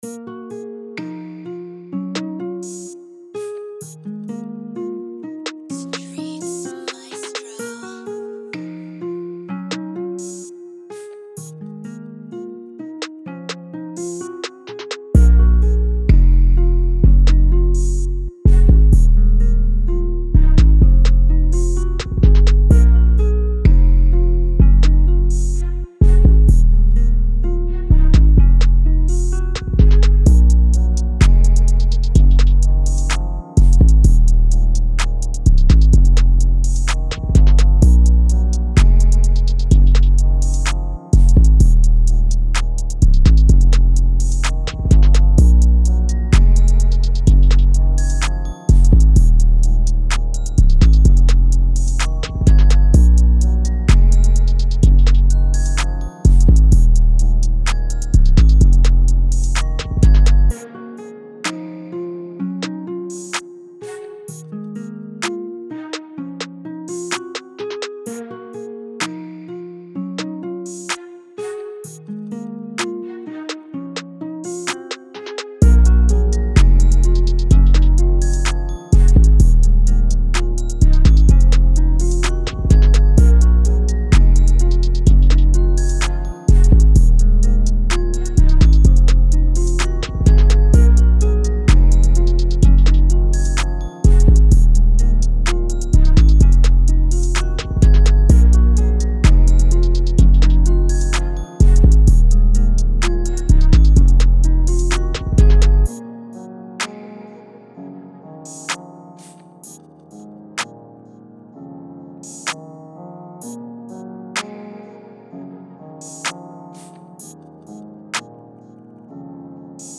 Intense Type Beat
Moods: Intense, dark, smooth
Genre: Rap
Tempo: 127
BPM 130